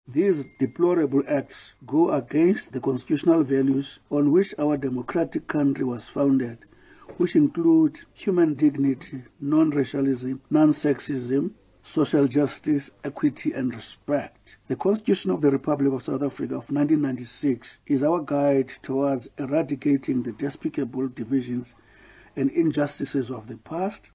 Minister in The Presidency Mondli Gungubele addressed a post-Cabinet briefing this week and said Cabinet strongly condemned the recent incidents of racism reported at two Western Cape educational institutions.